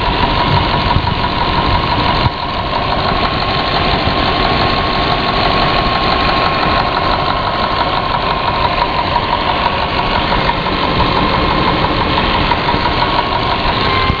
piston_slap1.wav